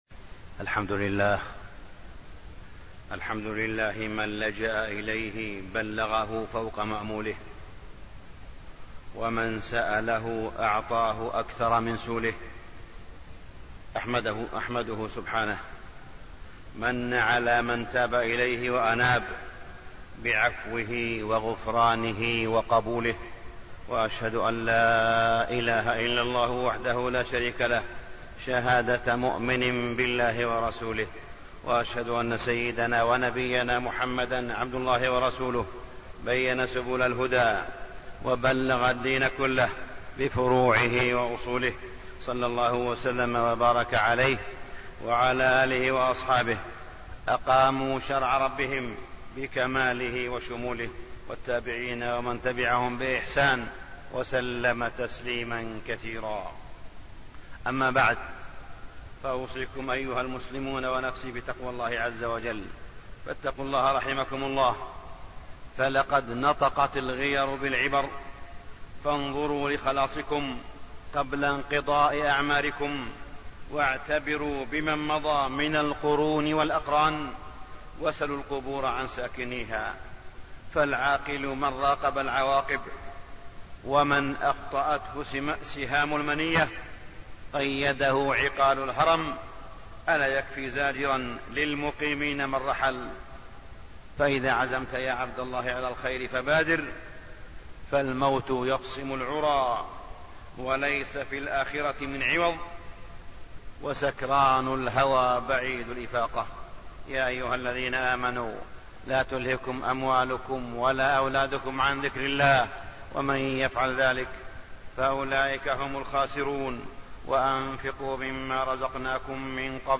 تاريخ النشر ١٥ رجب ١٤٣٢ هـ المكان: المسجد الحرام الشيخ: معالي الشيخ أ.د. صالح بن عبدالله بن حميد معالي الشيخ أ.د. صالح بن عبدالله بن حميد خطورة النميمة